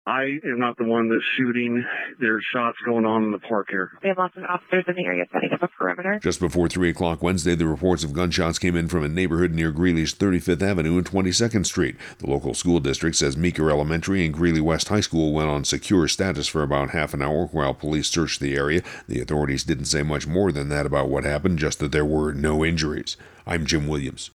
231102 Greeley radio traffic                            :06                                  Q:…up a perimeter…